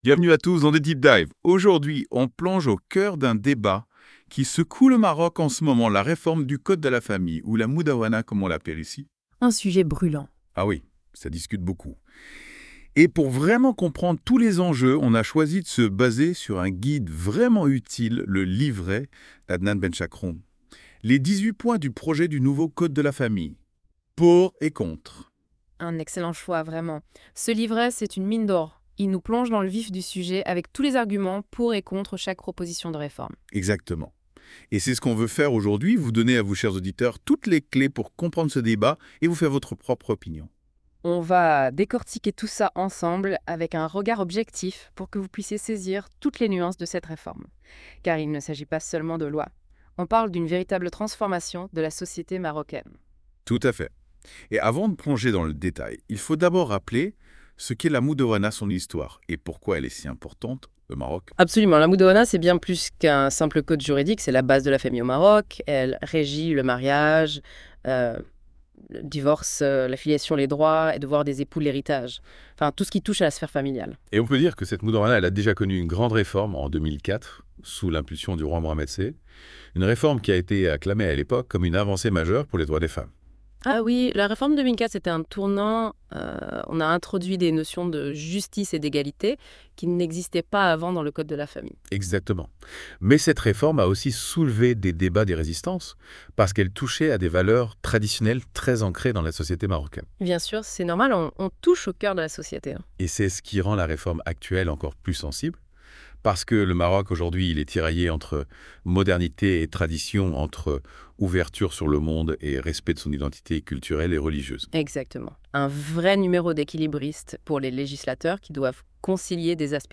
Débat en Podcast sur les 18 points du projet du nouveau Code de la famille à charge et à décharge
Les chroniqueurs de la Web Radio R212 en débattent dans ce podcast à travers ses questions :